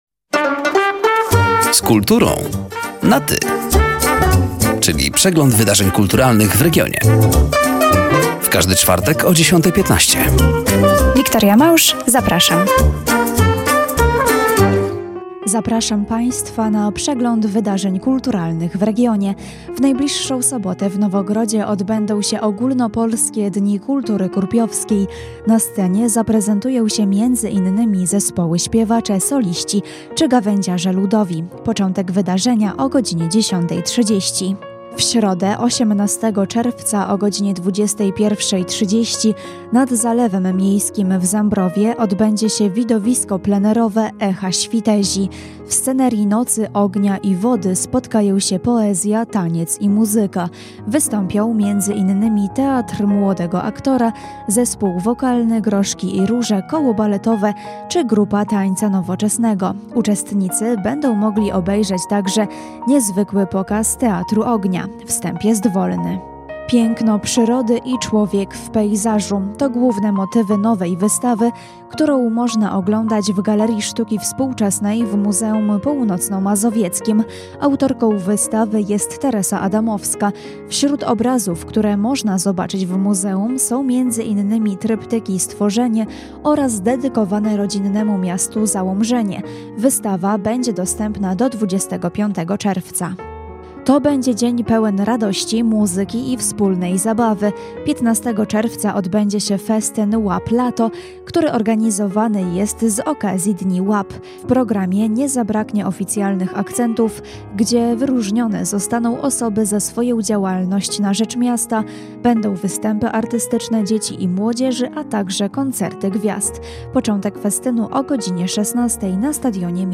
Zapraszamy do wysłuchania rozmowy oraz zapoznania się ze zbliżającymi wydarzeniami w regionie: